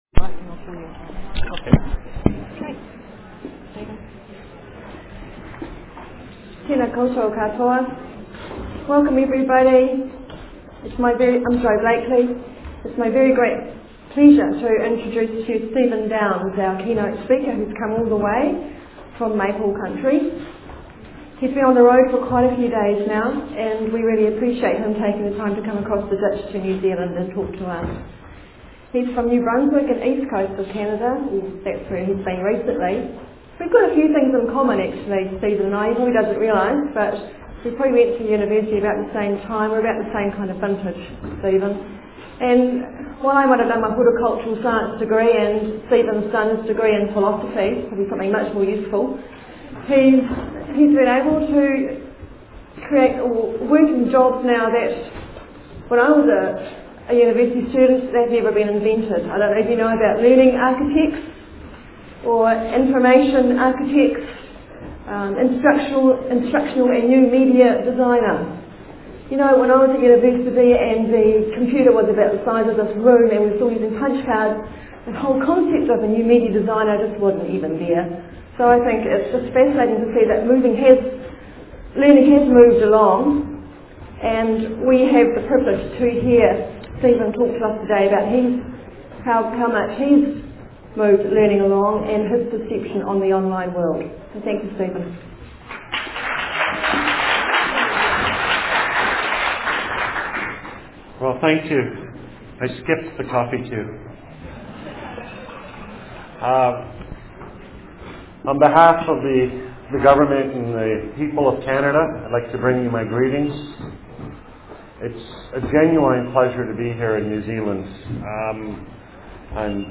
(Old style) [ Slides ] [ PDF ] [ Audio ] (New Style) [] eFest, Institutes of Technology and Polytechnics of New Zealand, Wellington, New Zealand, Keynote, Sept 27, 2006.